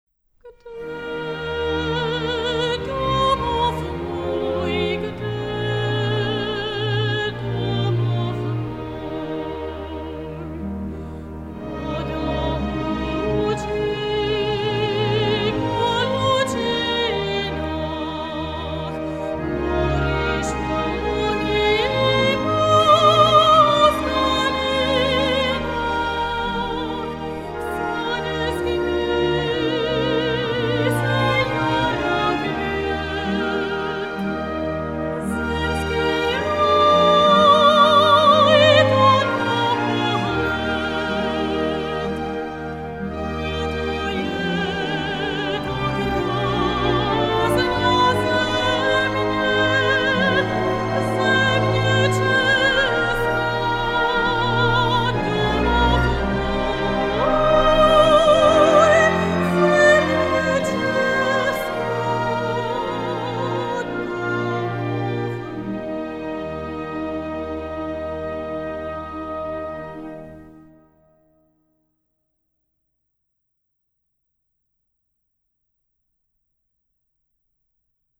ženský sólový zpěv